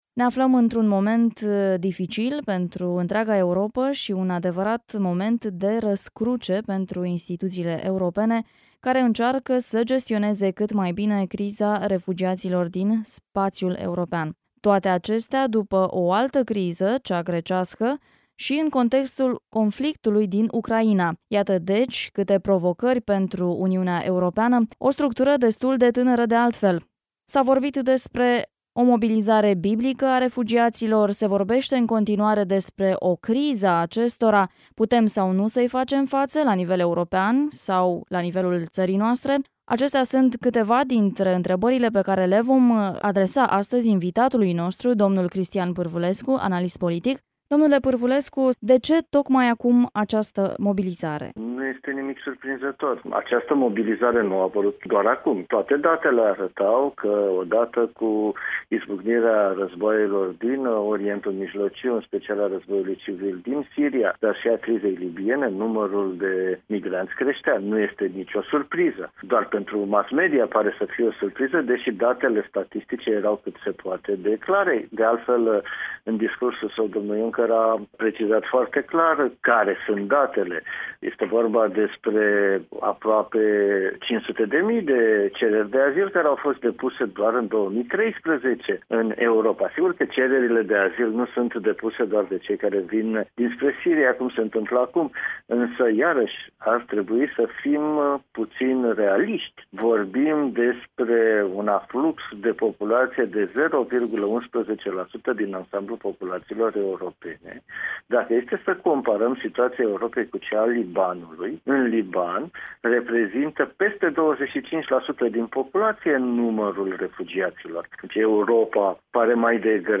(INTERVIU)